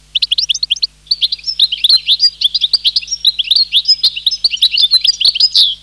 European Goldfinch
European-Goldfinch.mp3